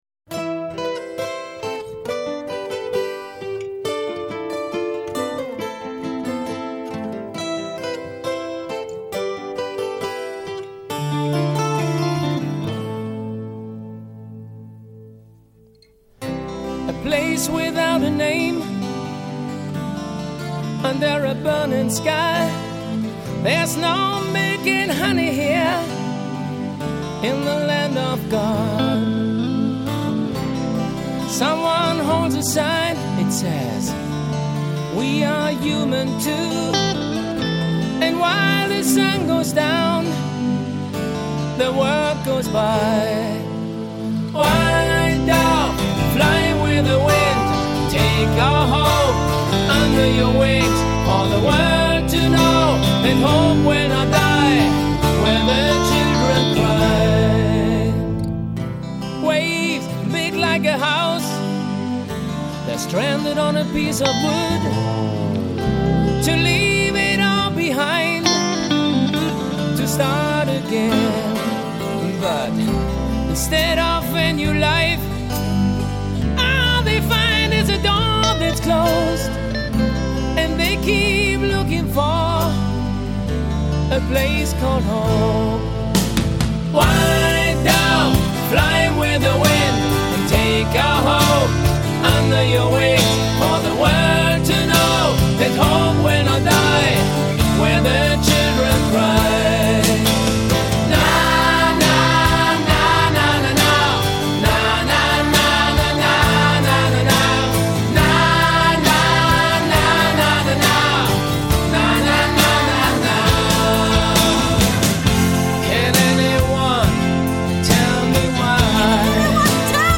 Жанр: hardrock